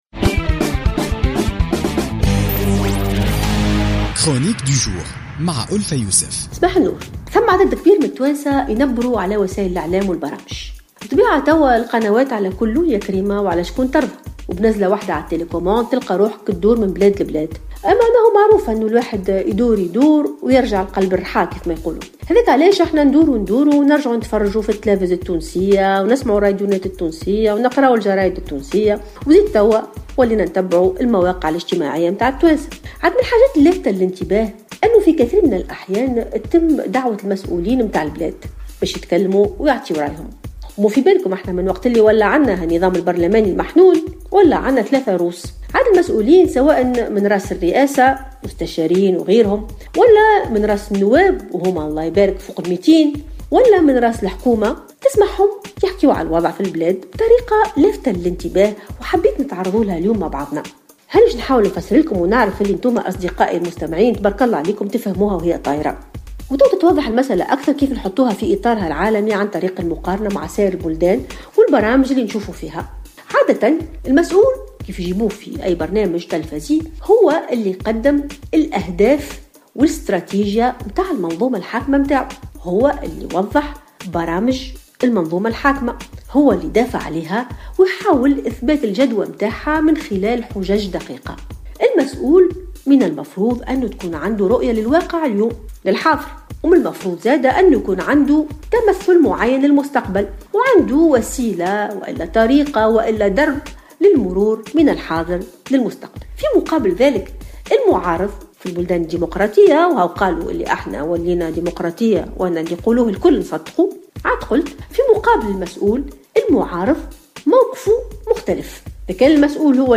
تطرقت الأستاذة الجامعية ألفة يوسف في افتتاحيتها اليوم الجمعة 19 فيفري 2016 لشعبوية المسؤولين السياسيين الذين يتم دعوتهم في المنابر الإعلامية في تونس وبدل أن يوضحوا برامجهم السياسية ورؤاهم للحاضر وتمثلهم للمستقبل أصبحوا يلعبون دور المعارض الذي ينتقد الوضع الحالي.